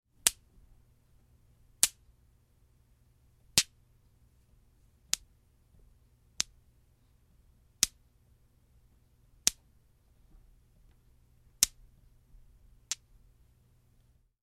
Звуки магнита
Магнитные шарики притягиваются друг к другу (серия)